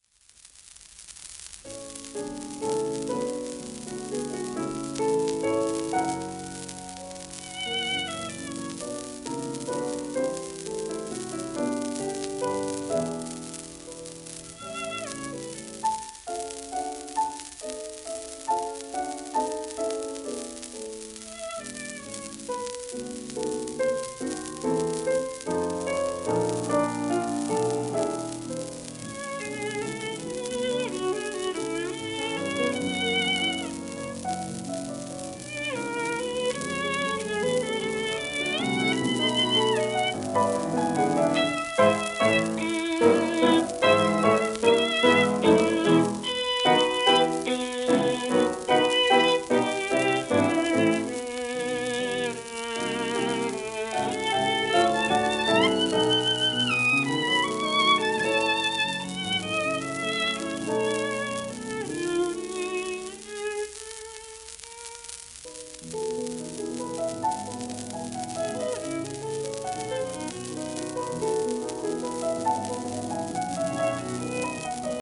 盤質A- *小キズ(１面目終端小キズ数クリック音あり),レーベルセンターホール部やや荒
1932年録音